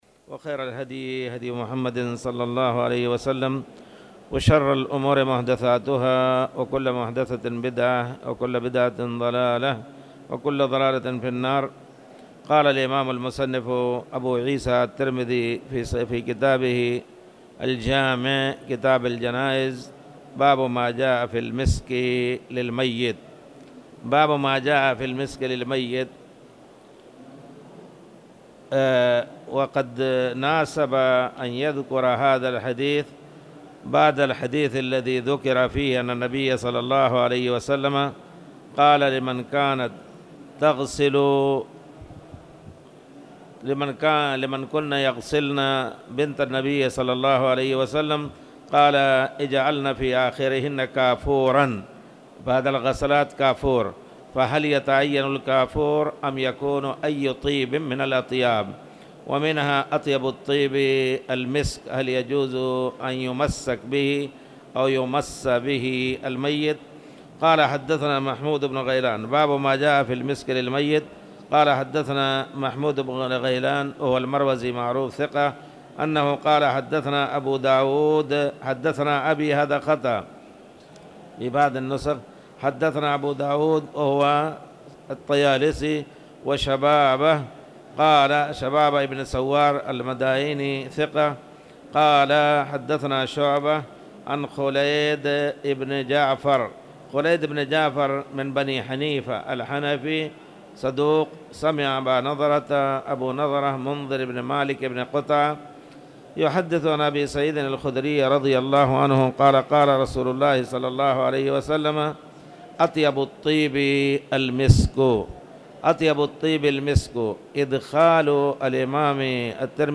تاريخ النشر ١٠ رمضان ١٤٣٧ هـ المكان: المسجد الحرام الشيخ